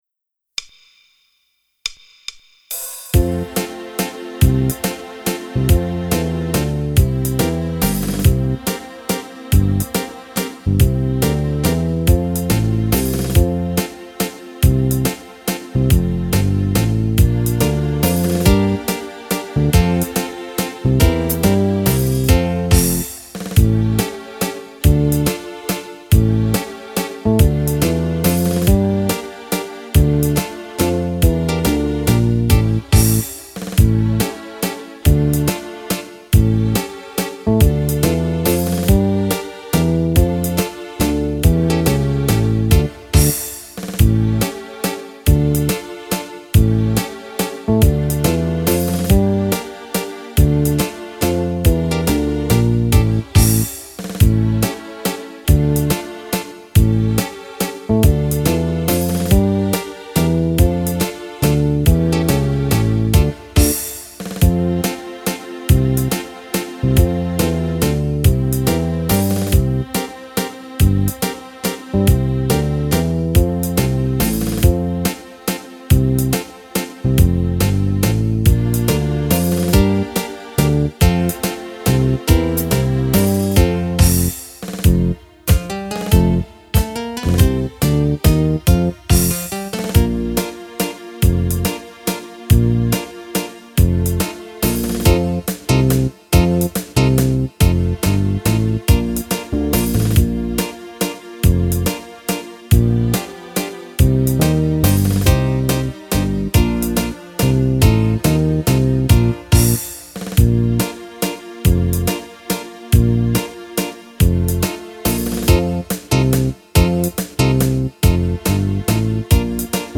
Mazurca variata
Fisarmonica